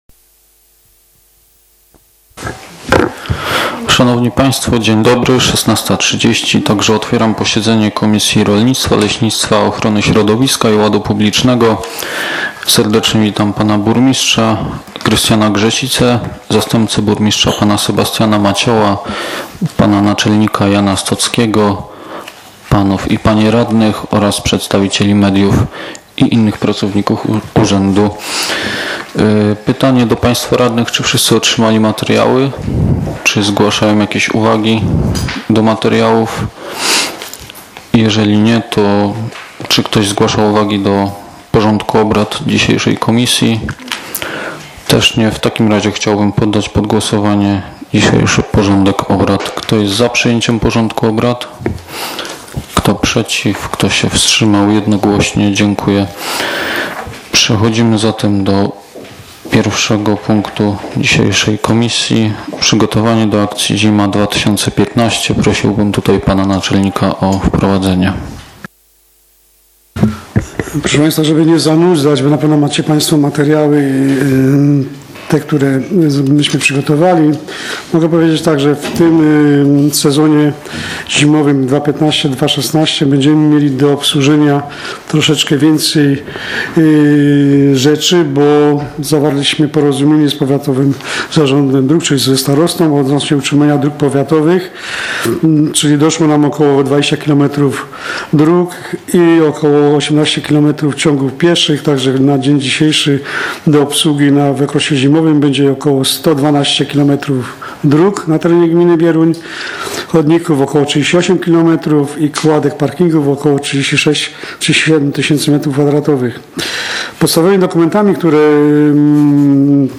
z posiedzenia Komisji Rolnictwa, Leśnictwa, Ochrony Środowiska i Ładu Publicznego w dniu 08.09.2015 r.